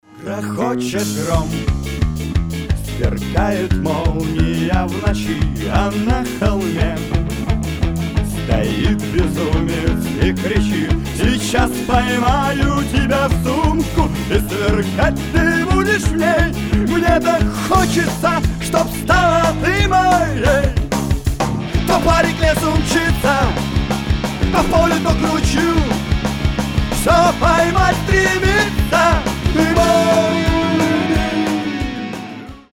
Панк-рок , Фолк-рок